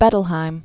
(bĕtl-hīm), Bruno 1903-1990.